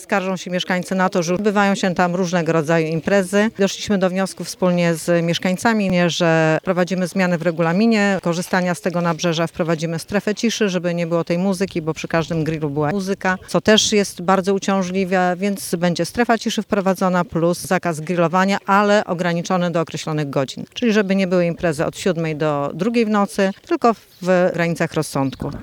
W sprawie planowanych regulacji głos zabrała Małgorzata Schwarz, wójt Gminy Kołbaskowo: